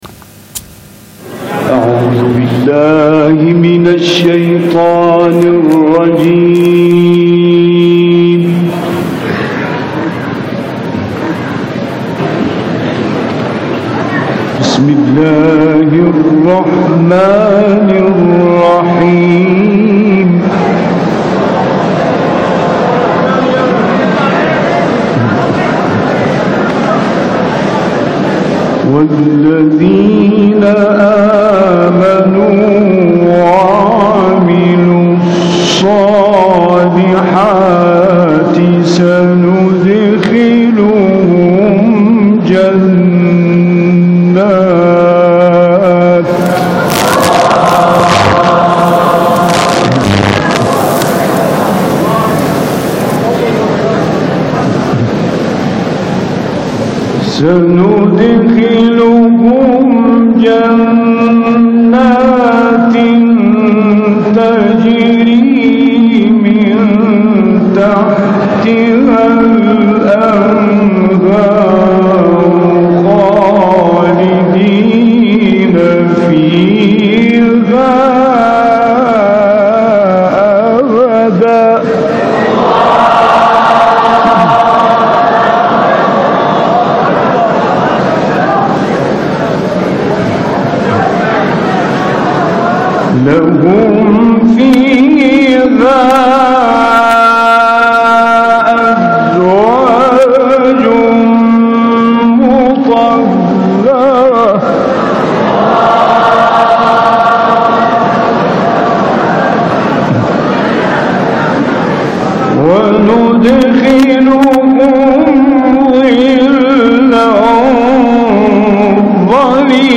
تلاوت سه قاری بنام مصری در مشهد مقدس
گروه شبکه اجتماعی: تلاوت شحات محمدانور، راغب مصطفی غلوش و فرج‌الله شاذلی که در حرم مطهر امام رضا(ع) اجرا شده است، ارائه می‌شود.